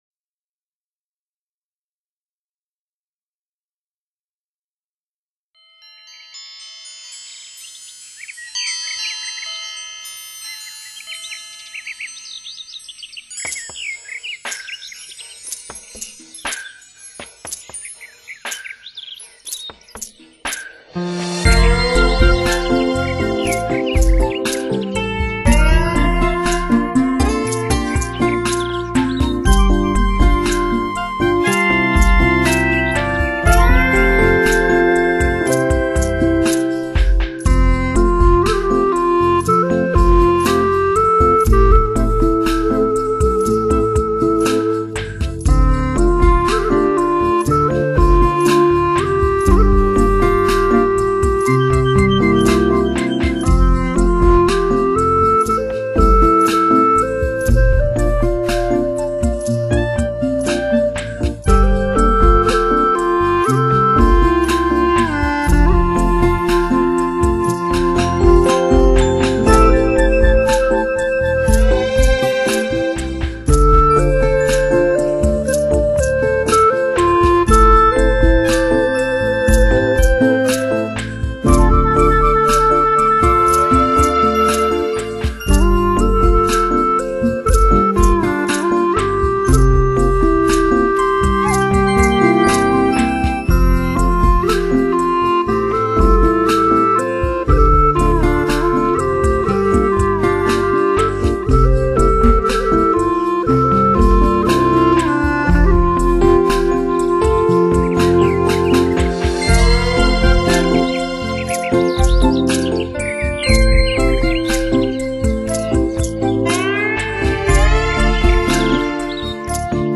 六声道环绕声
葫芦丝）(WAV所转低品质64k/wma